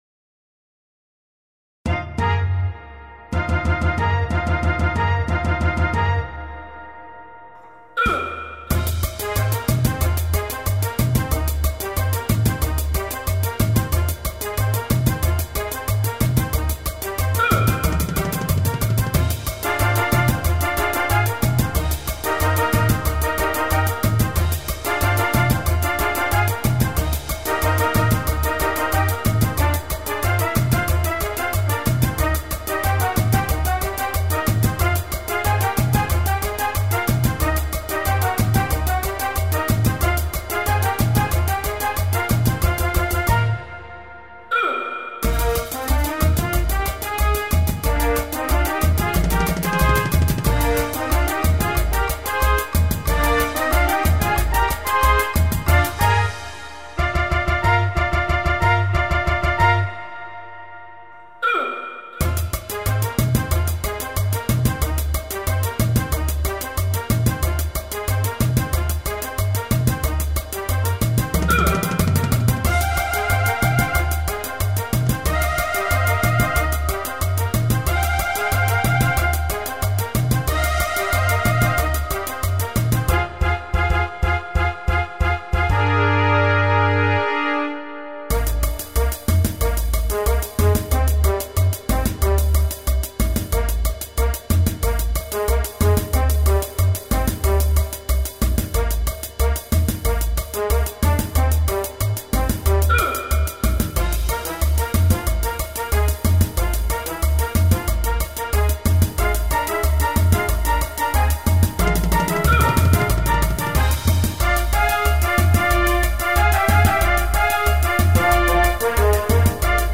Home > Music > Jazz > Bright > Smooth > Restless